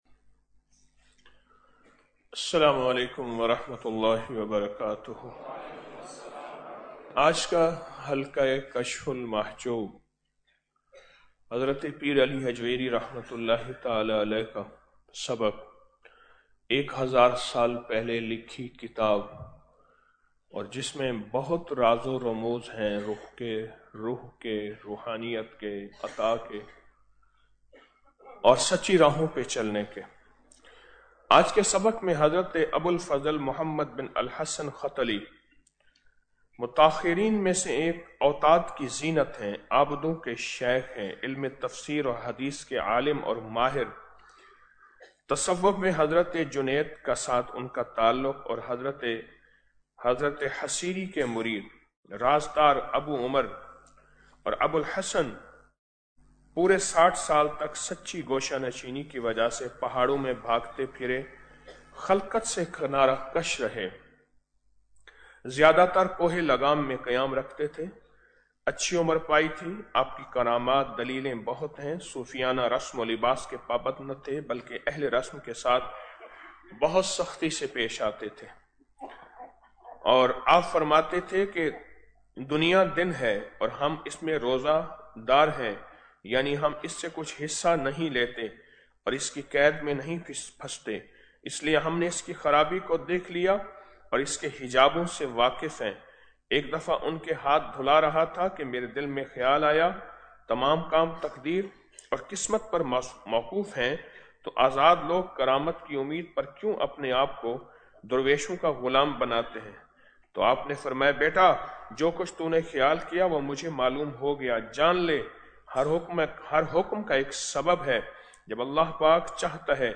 Audio Speech - 12 Ramadan After Salat Ul Fajar - 13 March 2025